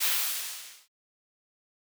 steam hisses - Marker #3.wav